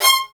STR ATTACK0F.wav